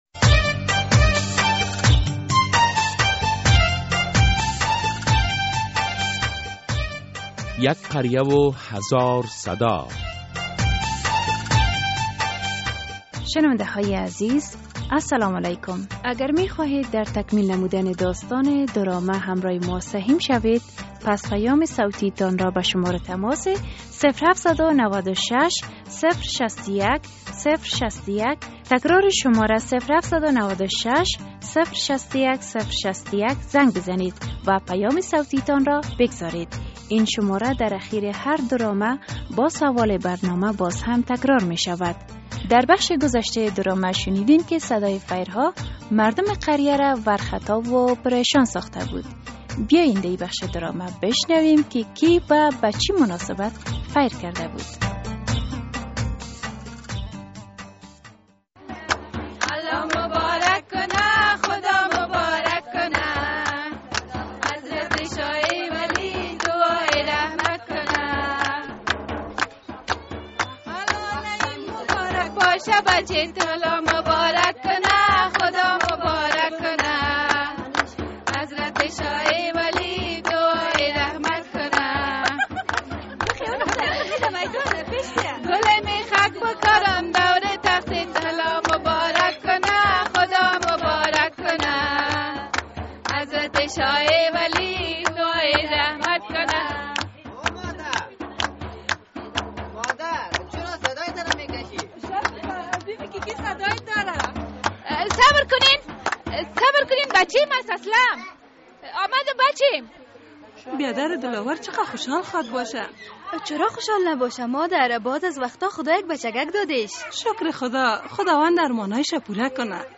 درامهء «یک قریه و هزار صدا» هر هفته به روز های دوشنبه ساعت 05:30 عصر بعد از نشر فشرده خبر ها از رادیو آزادی پخش می شود.